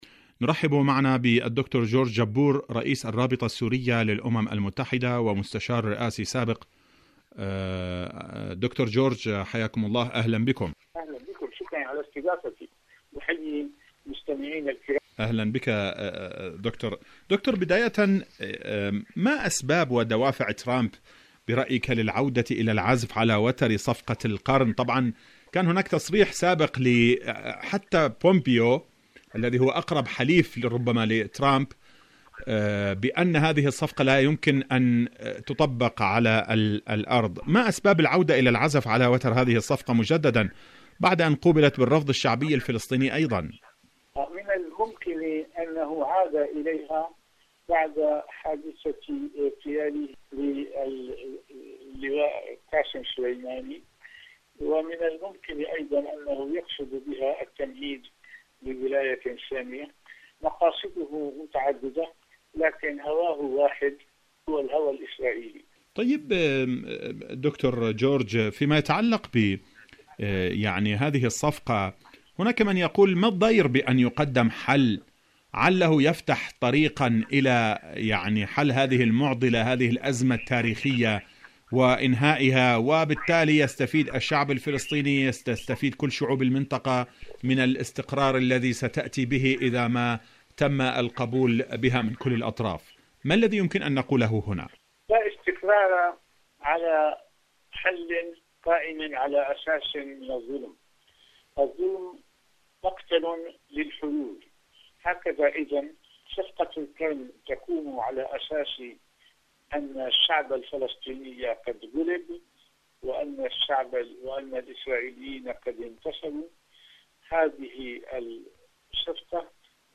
إذاعة طهران-أرض المقاومة: مقابلة إذاعية مع الدكتور جورج جبور رئيس الرابطة السورية للأمم المتحدة والمستشار الرئاسي السابق حول موضوع صفقة القرن ستتحول إلى لعنة